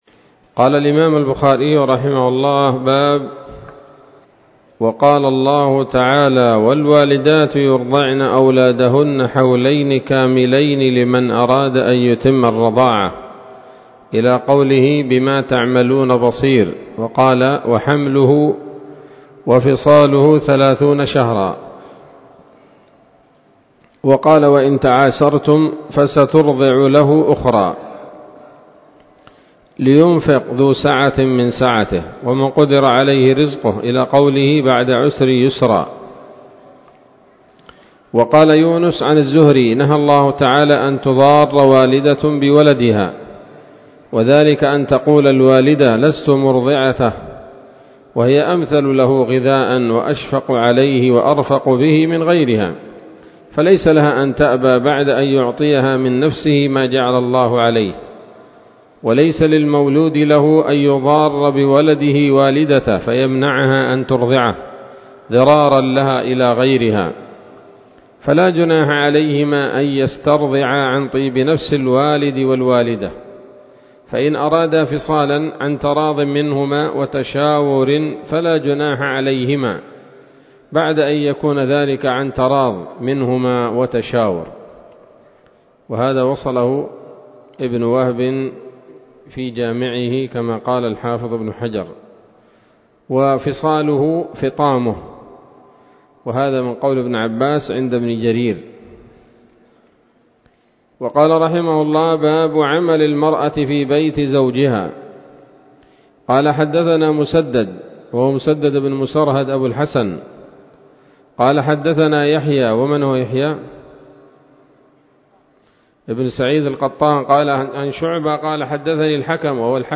الدرس الخامس من كتاب النفقات من صحيح الإمام البخاري